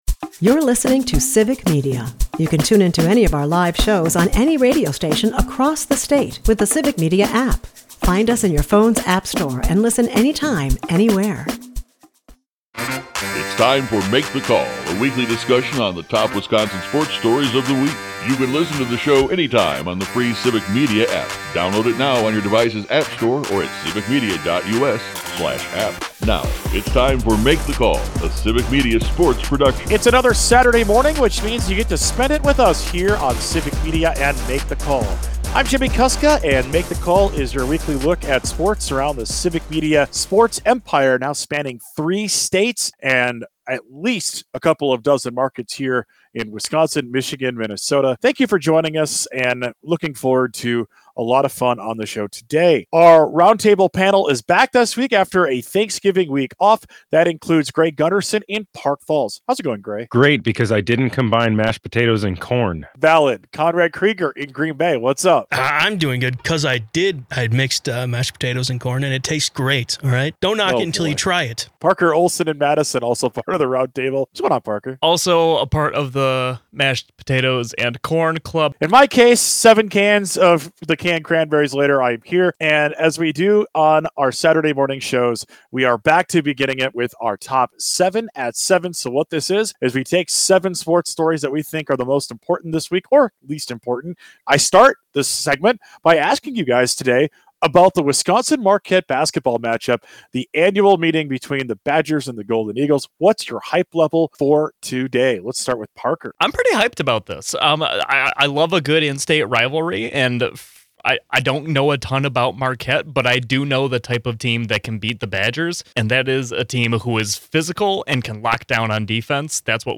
Start your weekend with the guys as they dive into the top stories in Wisconsin sports, ranging from Giannis rumours to… powder blue uniforms? Later, the roundtable dives into the massive Bears versus Packers matchup and discusses expectations for both the Packers and the Bucks.